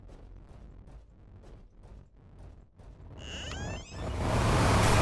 A-60_jumpscare.mp3